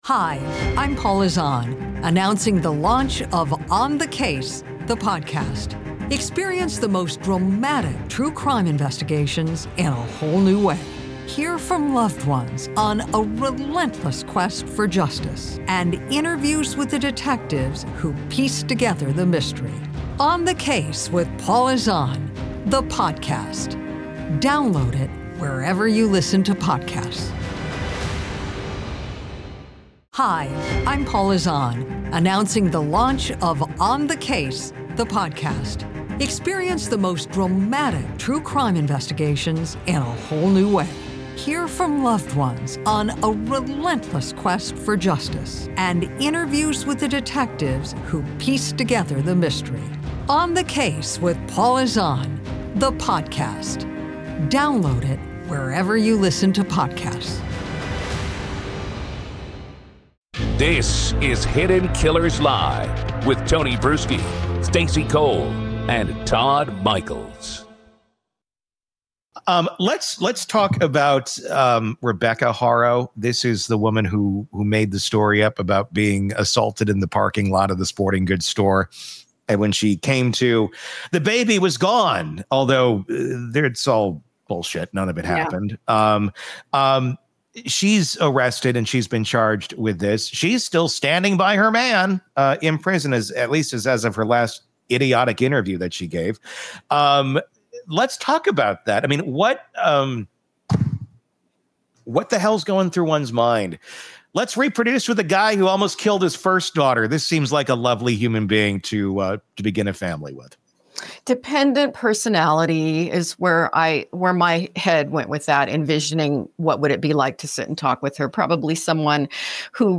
The panel debates the cycle of hurt people hurting people, asking: does her trauma explain her actions, or excuse them?